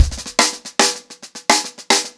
TheQuest-110BPM.1.wav